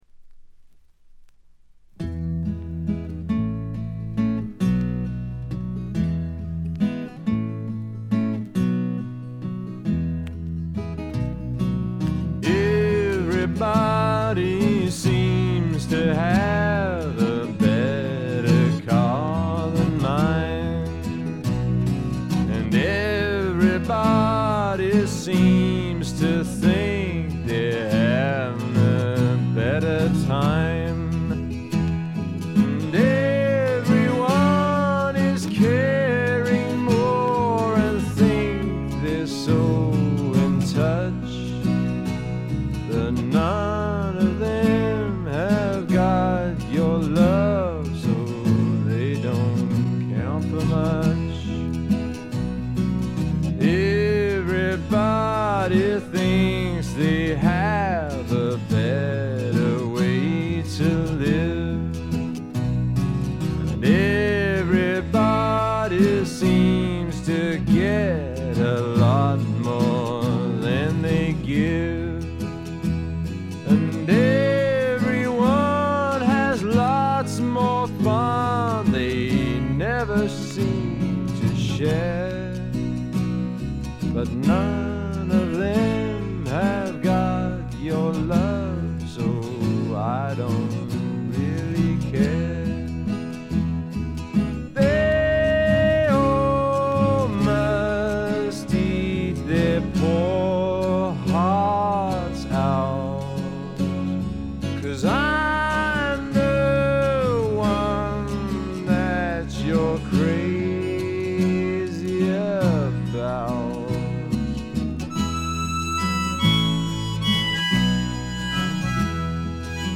静音部でのバックグラウンドノイズ。チリプチ少々。
胸に染みる弾き語り、アコースティックなフォークロック、とにかく全編が味わい深い名曲揃いで完成度はものすごく高いです。
試聴曲は現品からの取り込み音源です。